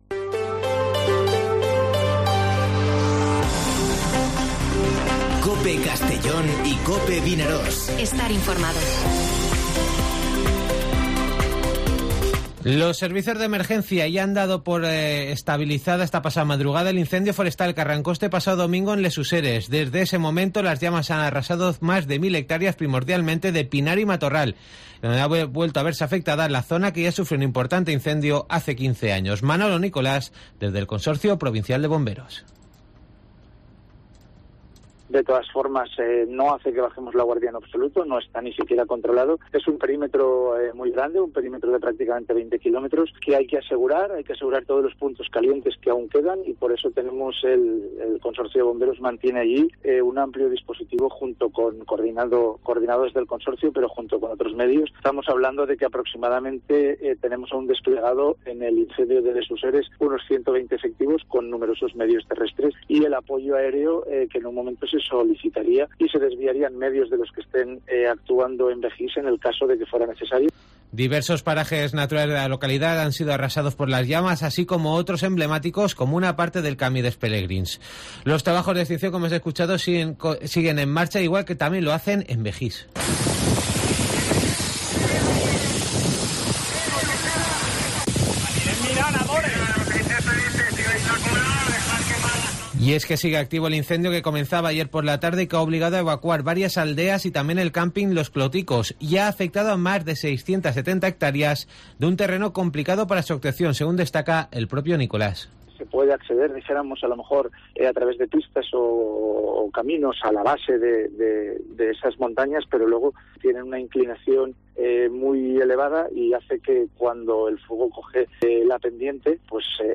Informativo Mediodía COPE en Castellón (16/08/2022)